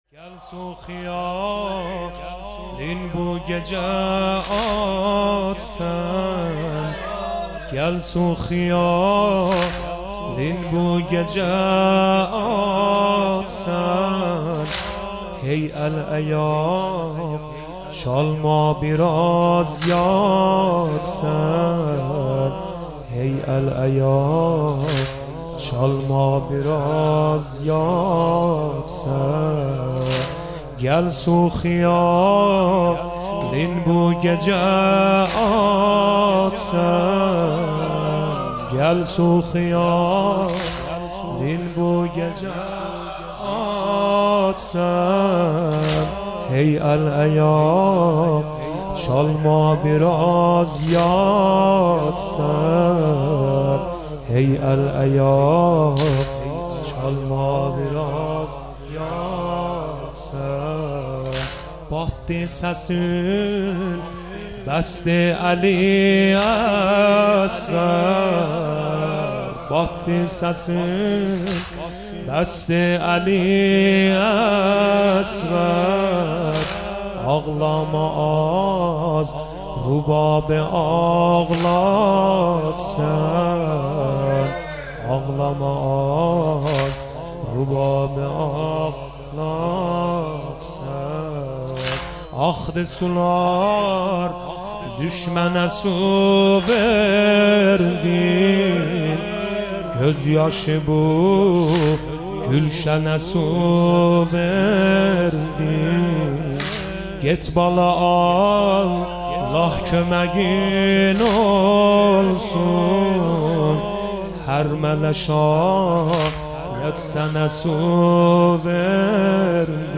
مراسم هفتگی مجمع حیدریون زنجان